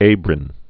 (ābrĭn)